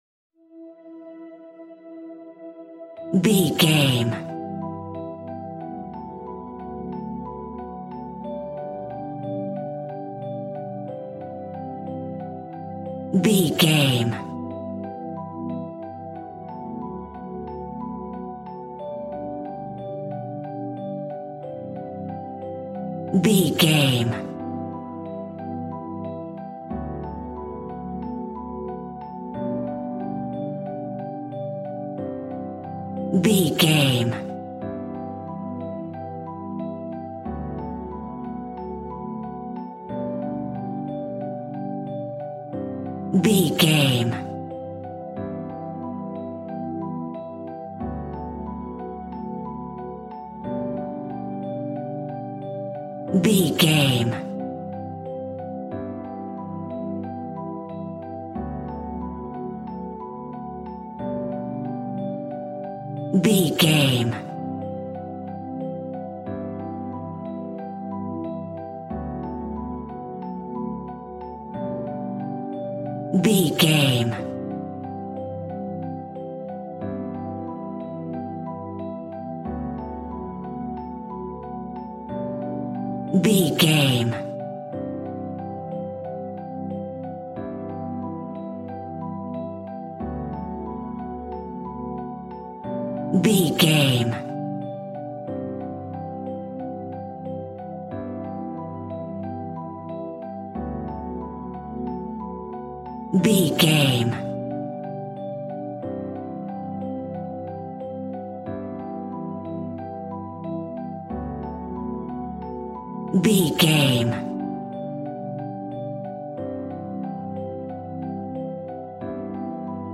Uplifting
Aeolian/Minor
ambient
piano